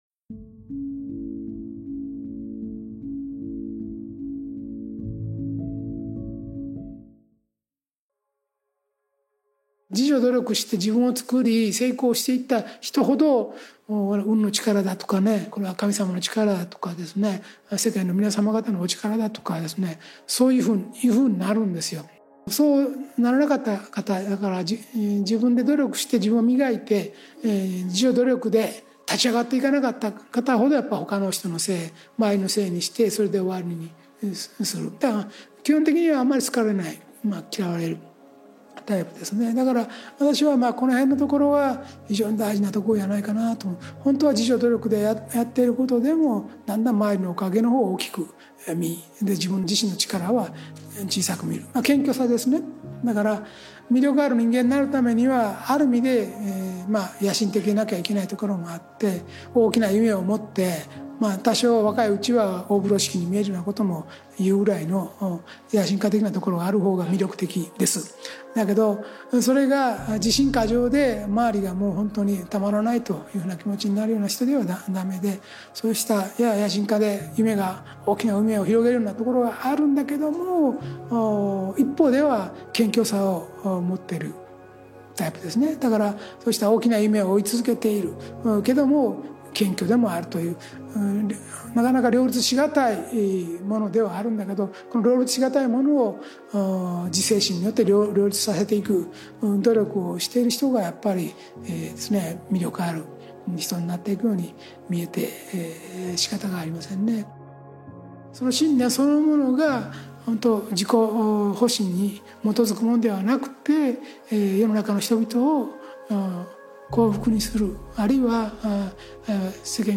ラジオ番組「天使のモーニングコール」で過去に放送された、幸福の科学 大川隆法総裁の説法集です。